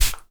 spray_bottle_03.wav